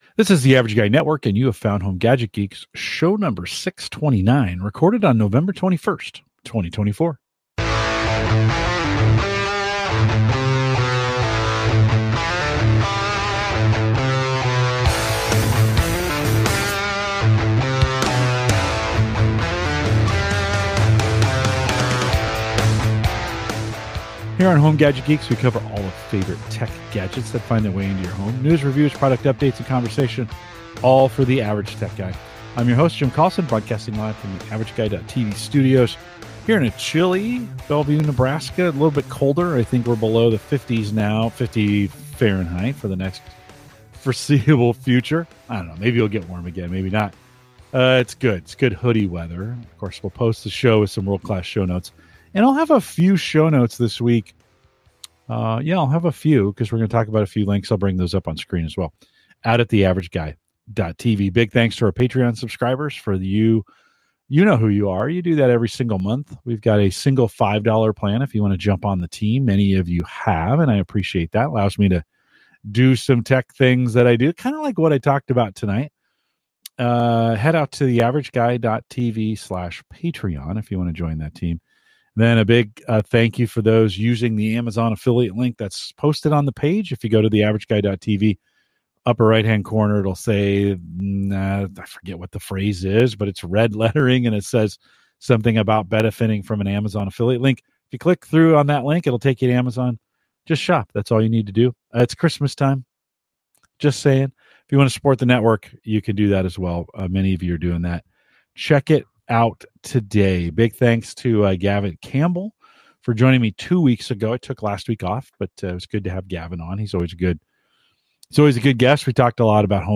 Just me this week!